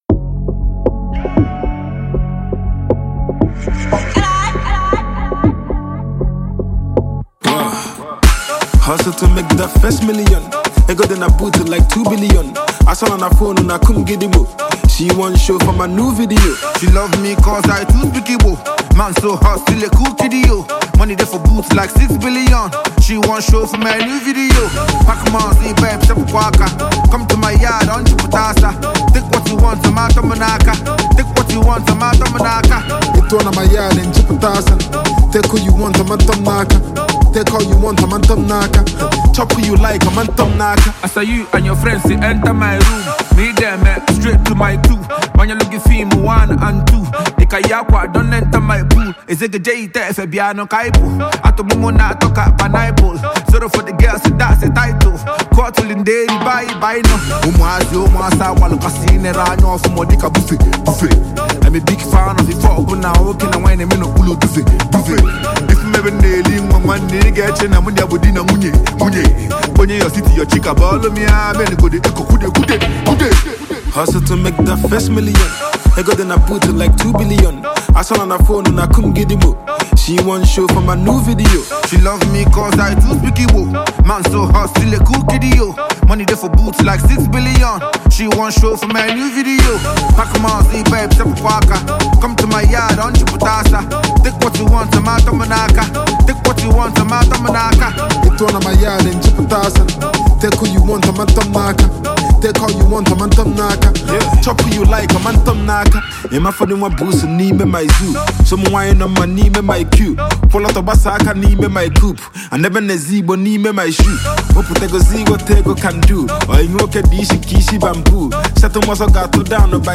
Nigerian indigenous rapper and lyricist